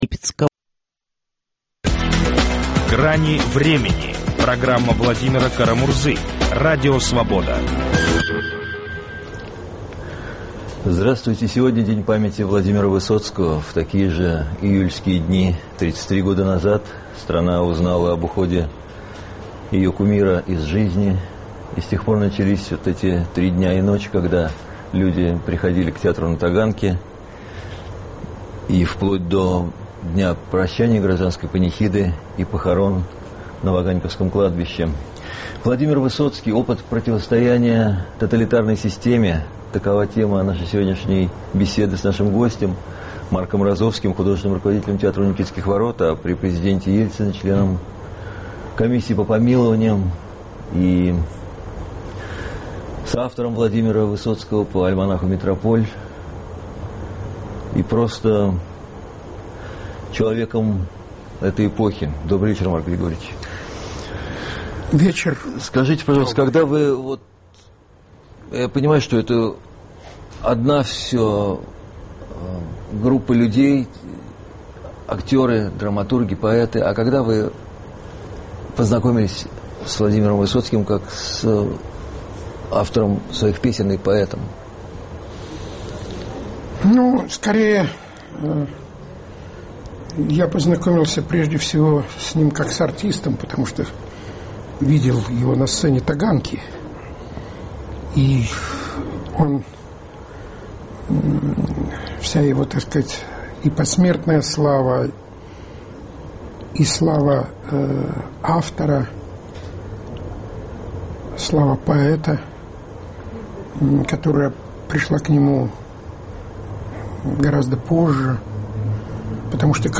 Владимир Высоцкий: опыт противостояния тоталитарной системе. Беседуем с режиссером Марком Розовским, бывшим членом Комиссии по помилованию при президенте Борисе Ельцине.